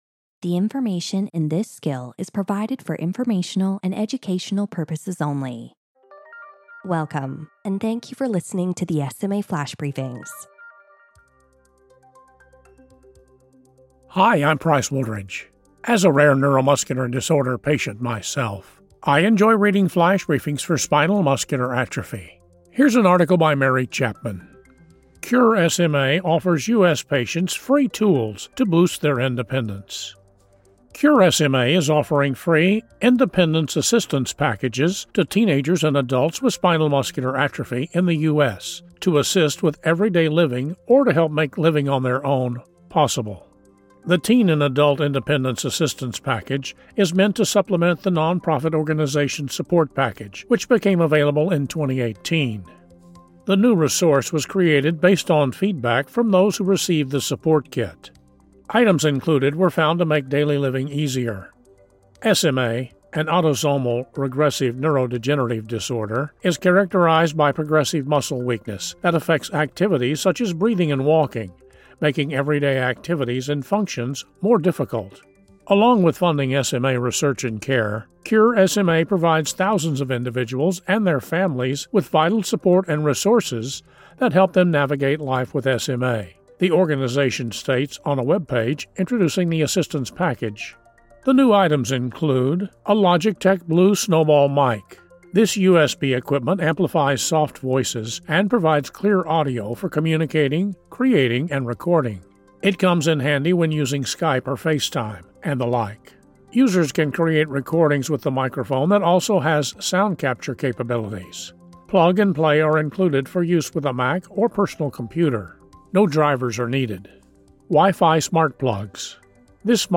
reads a column about the realities of living with SMA and managing fatigue.